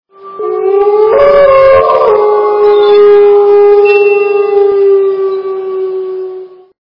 » Звуки » Природа животные » Волк - Вой волка
При прослушивании Волк - Вой волка качество понижено и присутствуют гудки.
Звук Волк - Вой волка